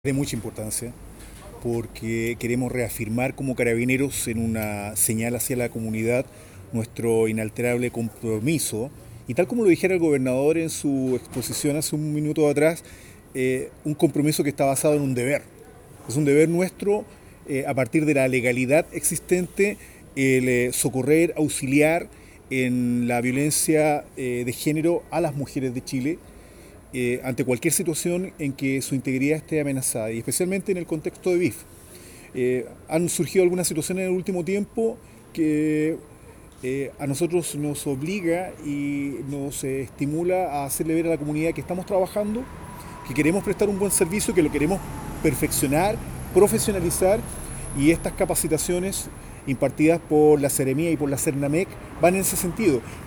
cuña-General-de-Carabineros.mp3